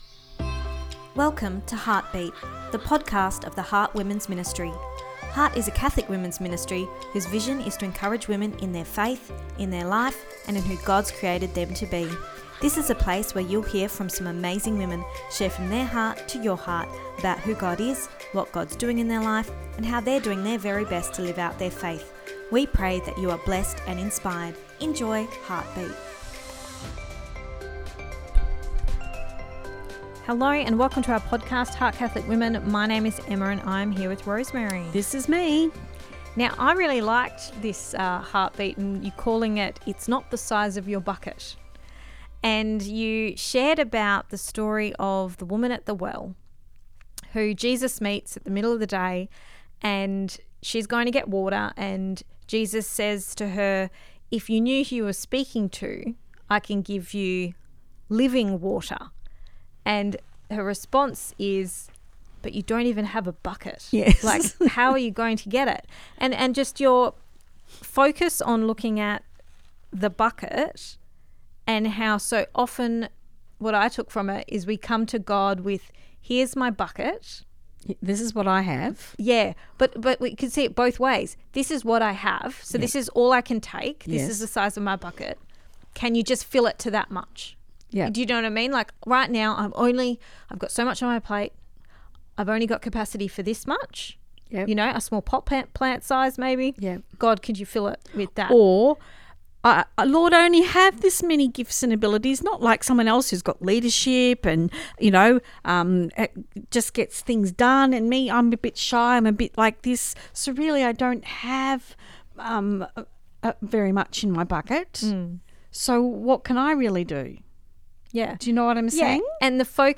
Ep258 Pt2 (Our Chat) – It’s Not the Size of Your Bucket